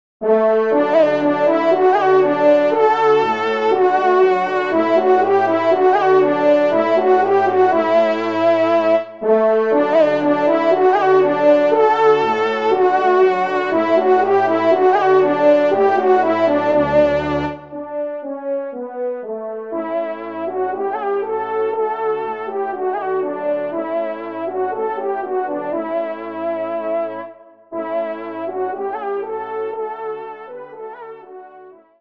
Genre : Divertissement pour Trompes ou Cors
Trompe 1               Trompe 1 (6/8)